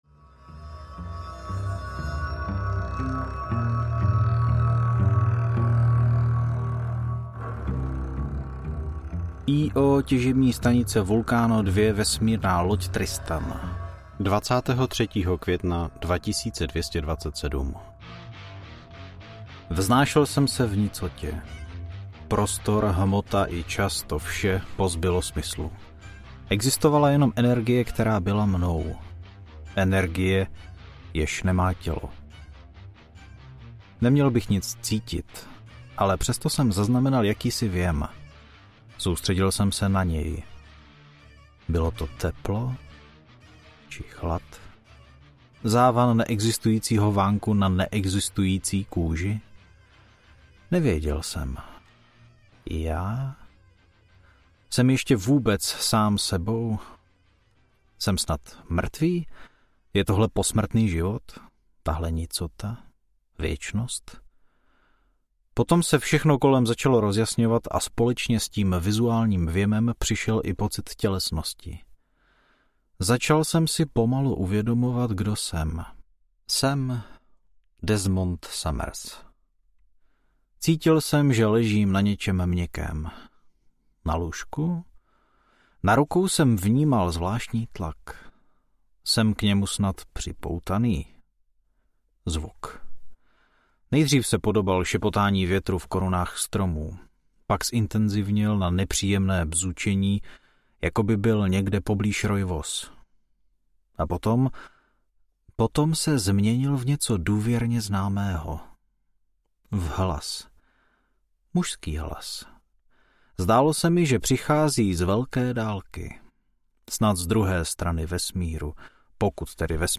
Světlo pulsaru audiokniha
Ukázka z knihy